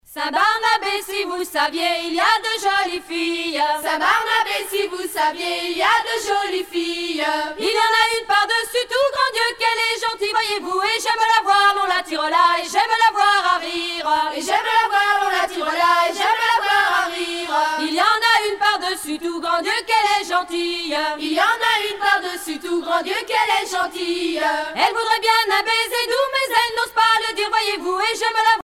Chant du Pays de Mur
Genre laisse
Pièce musicale éditée